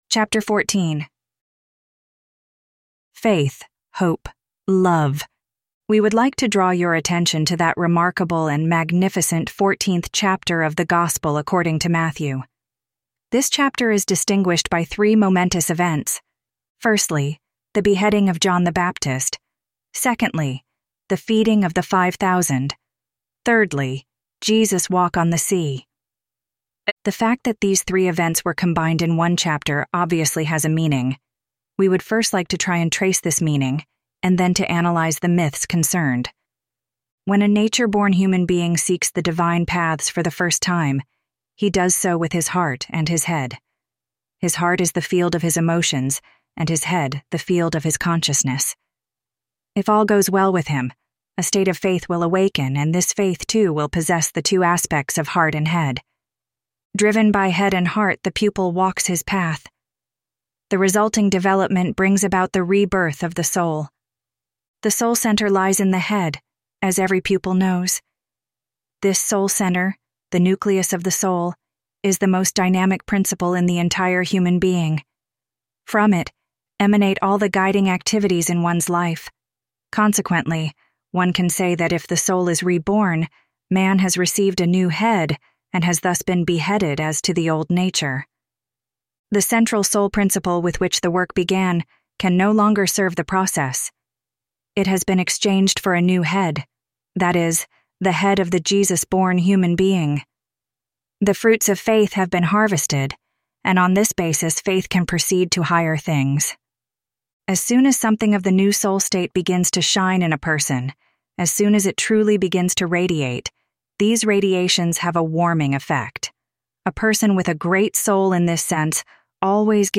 Audio Books of the Golden Rosycross